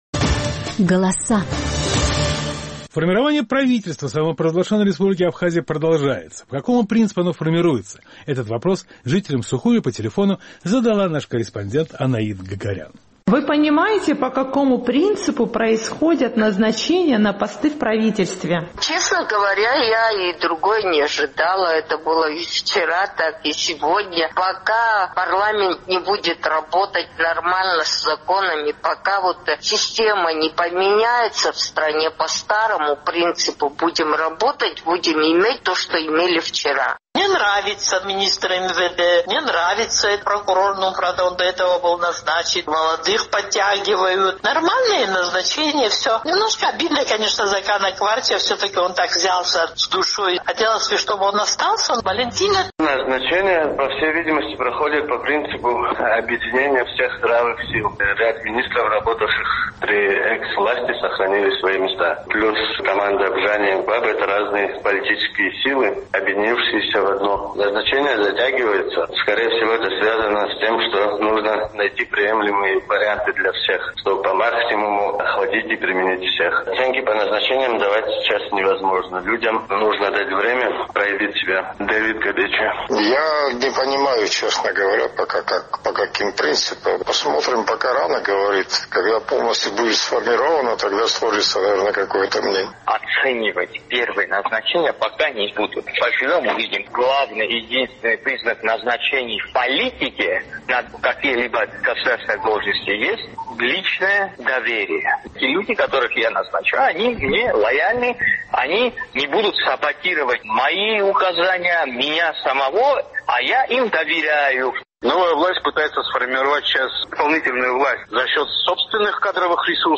Этот вопрос жителям Сухума по телефону задала наш абхазский корреспондент.